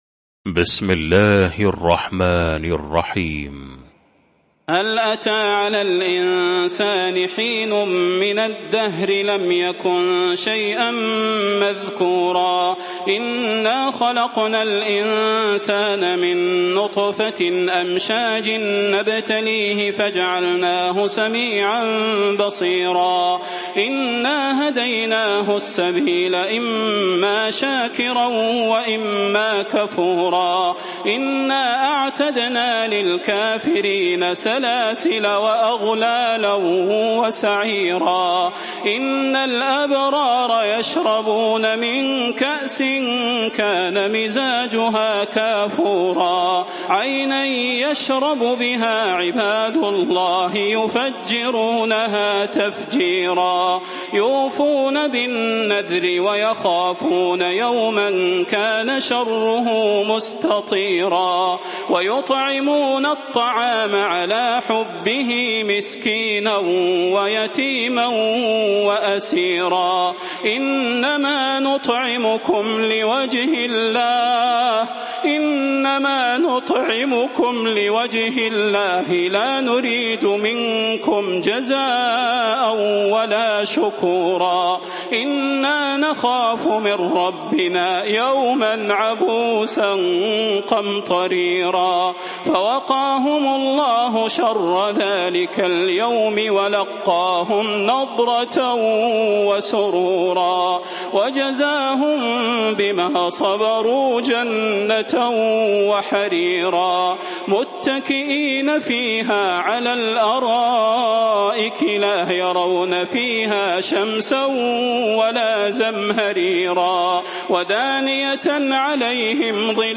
المكان: المسجد النبوي الشيخ: فضيلة الشيخ د. صلاح بن محمد البدير فضيلة الشيخ د. صلاح بن محمد البدير الإنسان